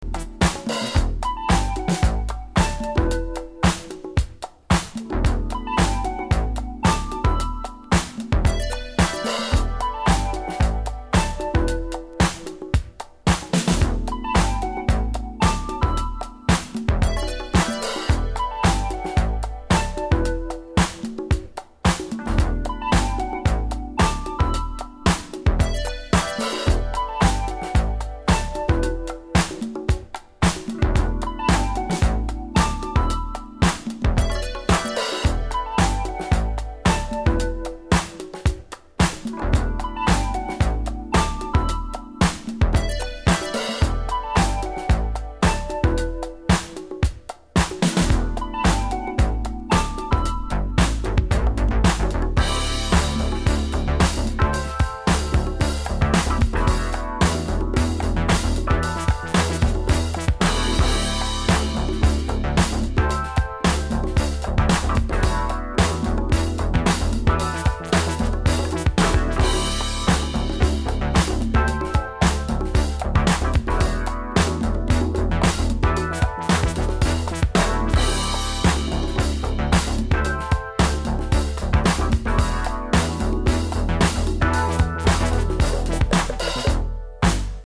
アナログ・ライクなシンセ・サウンドを前面に出した80'sテイストのブギー・ディスコ〜ファンクを披露！